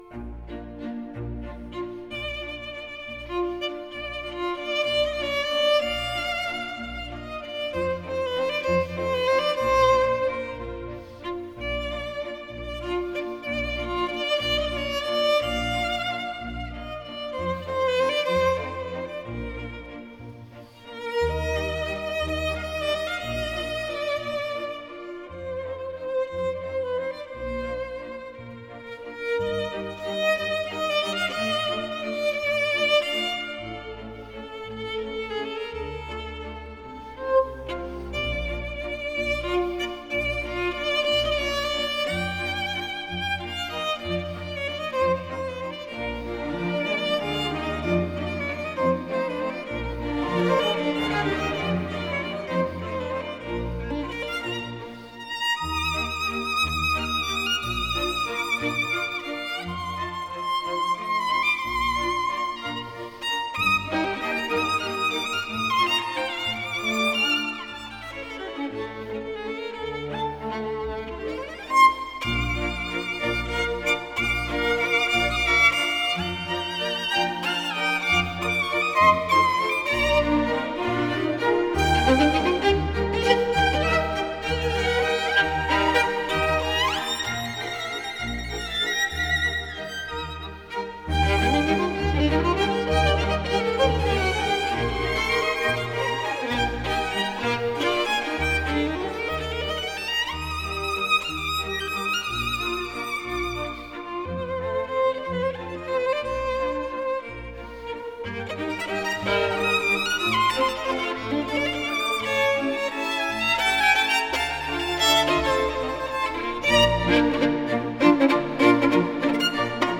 录音属发烧示范级，堂音丰富优美，常被各国专家级音响迷当作试机标竿！